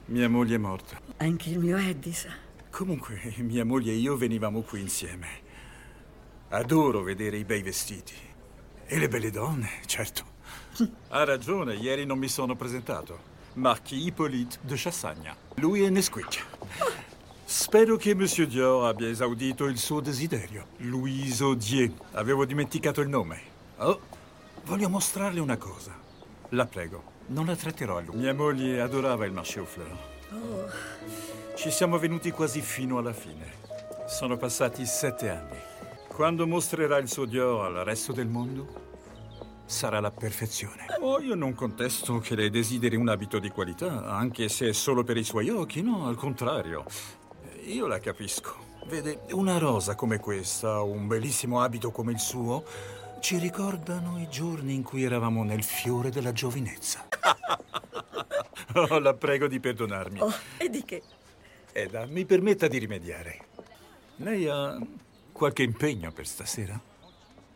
in cui doppia Lambert Wilson.